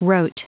Prononciation du mot rote en anglais (fichier audio)
Prononciation du mot : rote